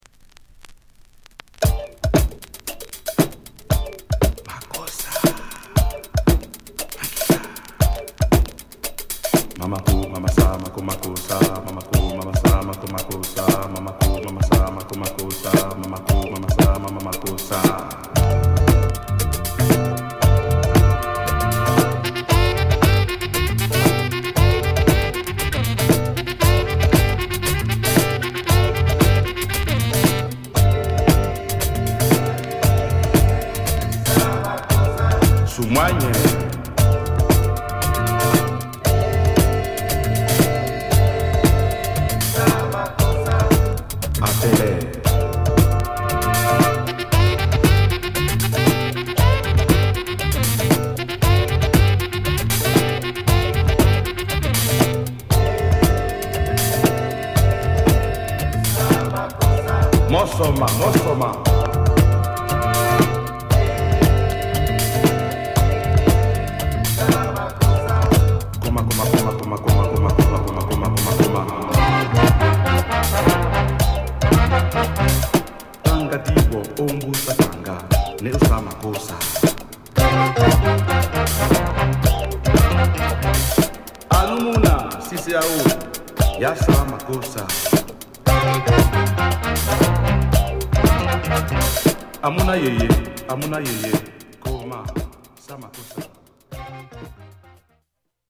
イントロブレイクから文句無し！